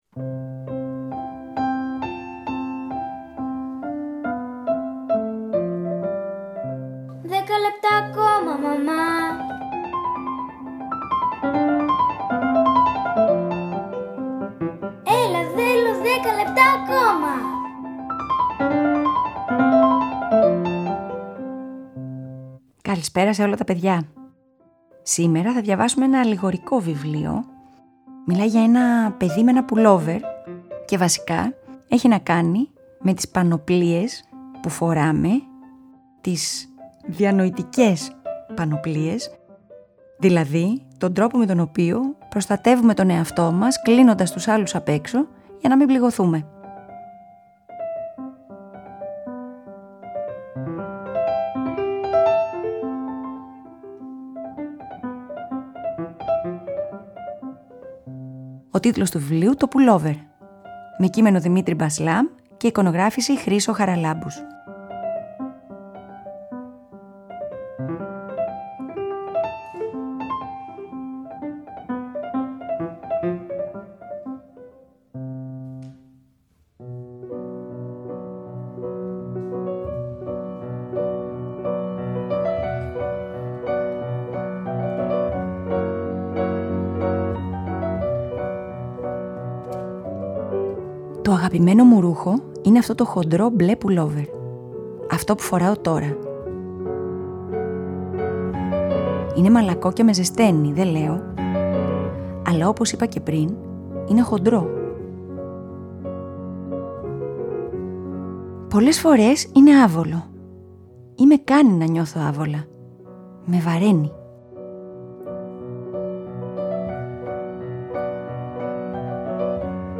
Σήμερα θα διαβάσουμε ένα βιβλίο για τους φόβους, τις ανασφάλειες και τη μοναξιά που κουβαλάμε από μικρά παιδιά.